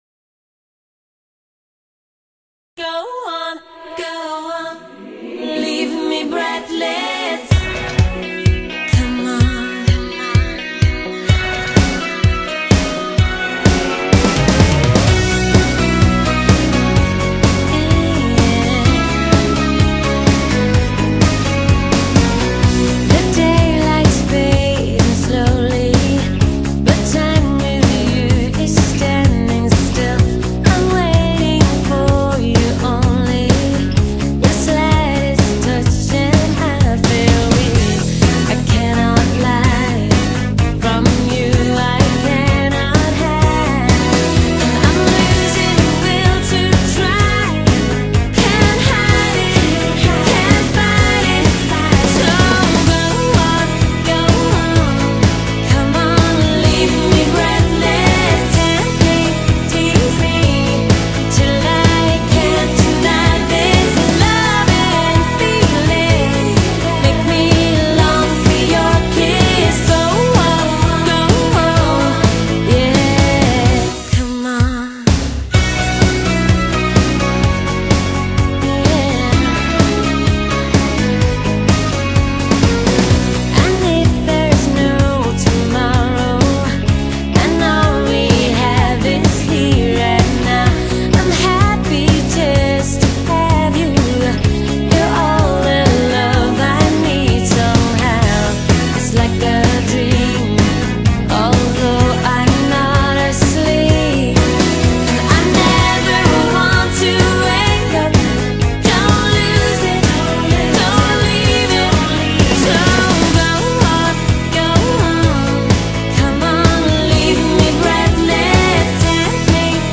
BPM127
Audio QualityCut From Video